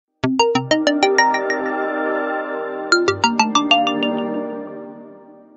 iPhone Ringtones